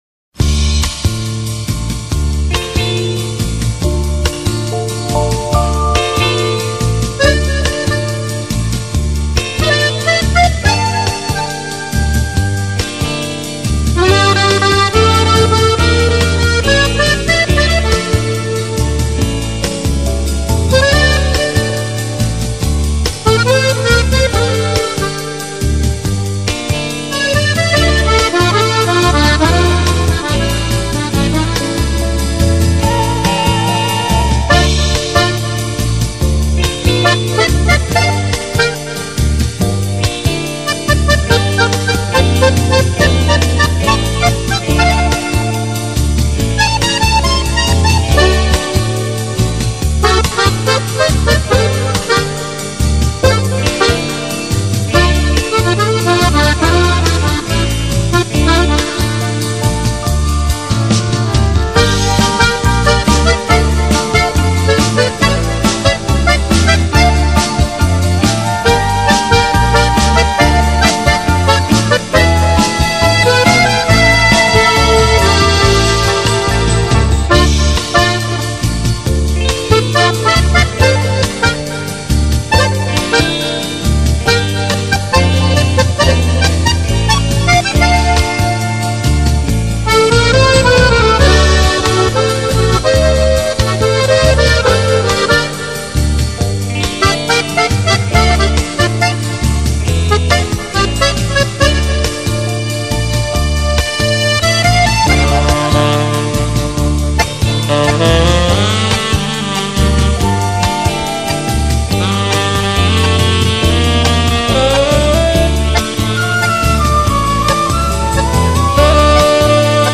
Красивая_музыка__аккордеонMP3_128K
Krasivaya_muzyka__akkordeonMP3_128K.mp3